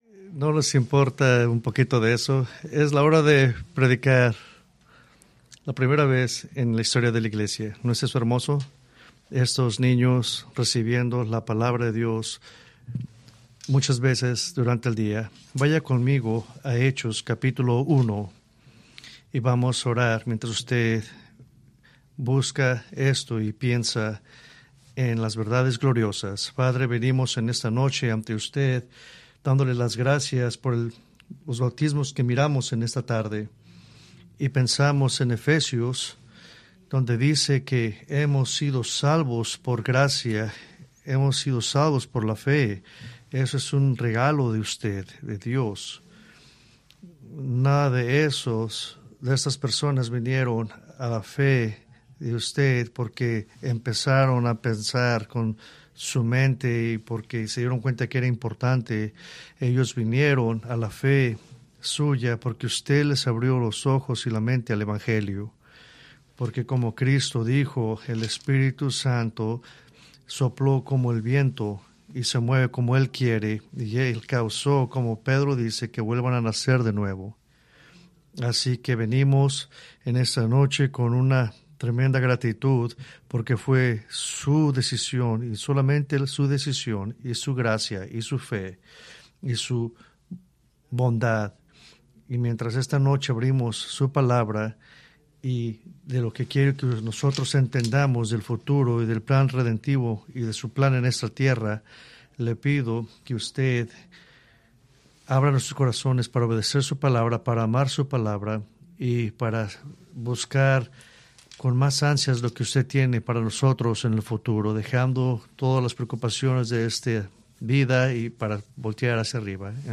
Preached November 17, 2024 from Escrituras seleccionadas